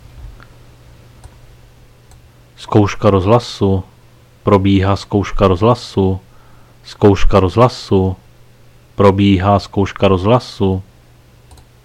Záznam hlášení místního rozhlasu 1.6.2022